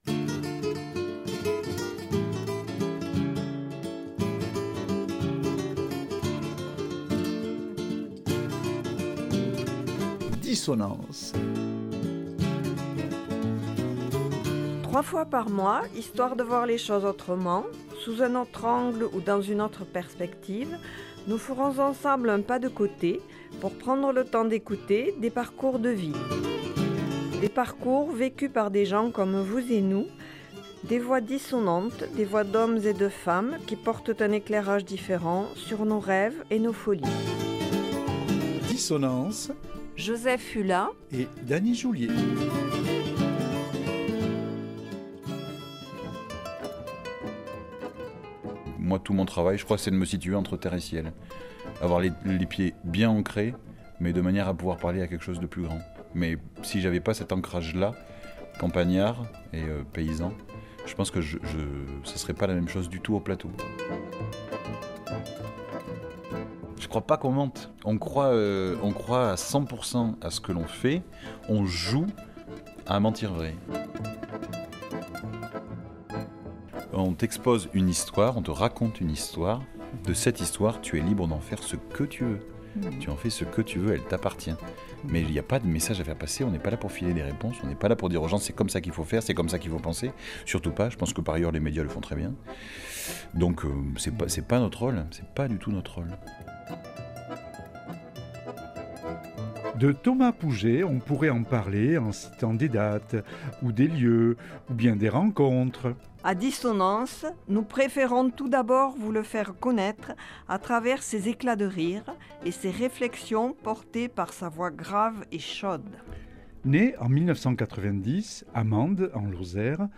Dans Dissonances, nous préférons tout d’abord vous le faire connaitre à travers ses éclats de rire, et ses réflexions portées par sa voix grave et chaude.
Cet entretien a été enregistré en mars 2024.